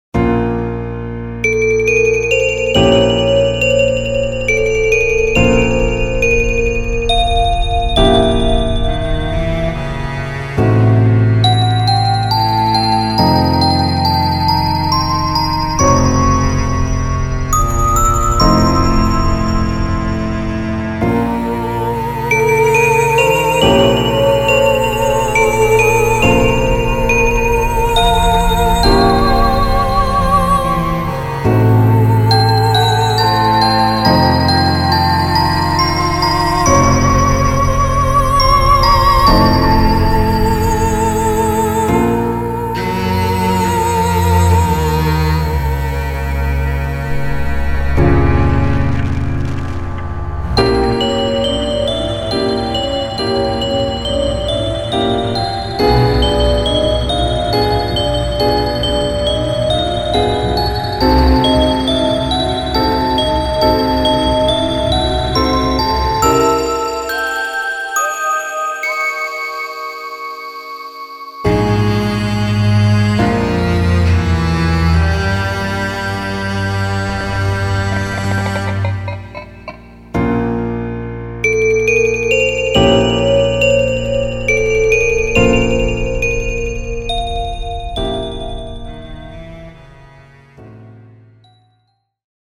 イベントシーン ホラー・不気味・不穏
フェードアウト版のmp3を、こちらのページにて無料で配布しています。